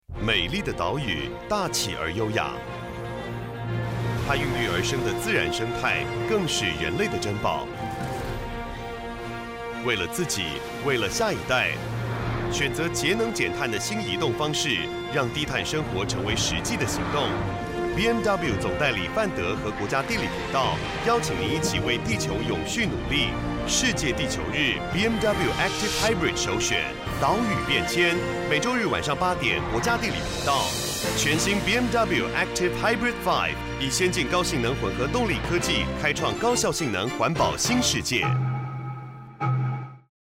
國語配音 男性配音員
質感、專業
無論是充滿激情的促銷廣告，還是柔和動人的情感訴求，他都能以細膩的聲音變化切換氛圍，將廣告訊息完美呈現。
他以字正腔圓、語速與情緒控制得當著稱，確保廣告文案的每個字都傳遞清晰，並且與品牌定位高度契合。